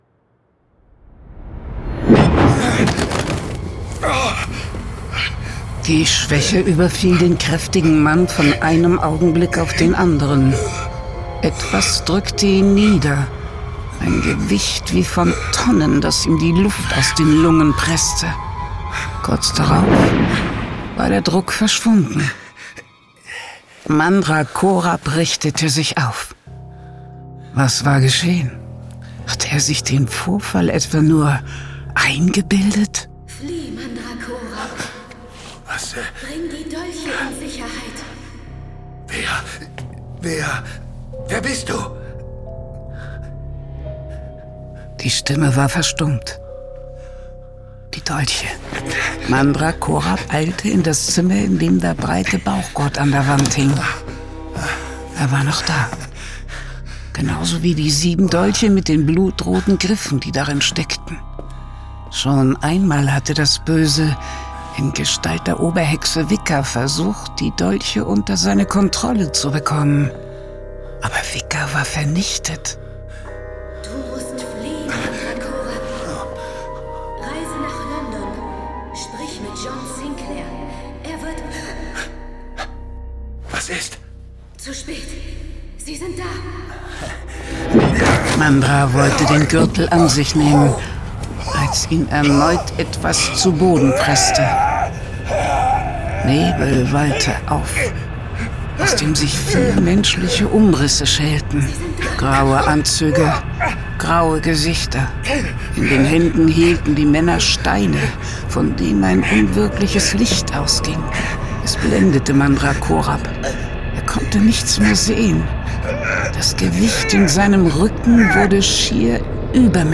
Hörspiel.